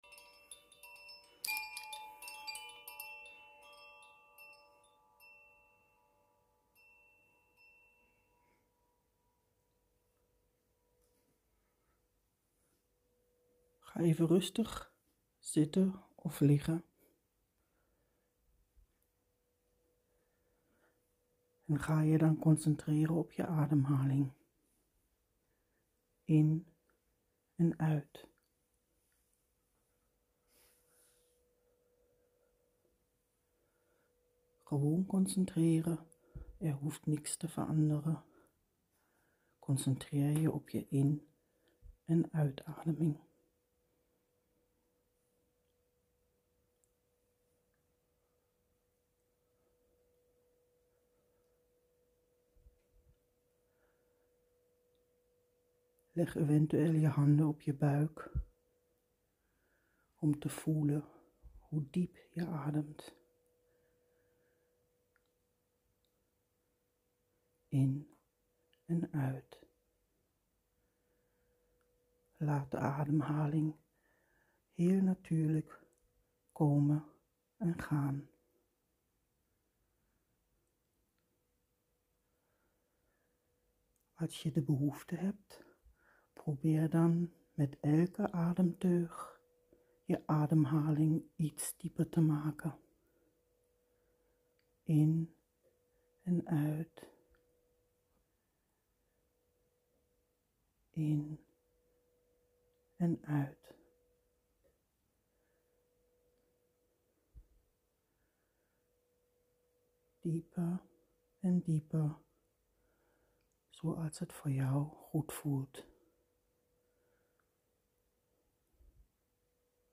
Ademoefening.mp3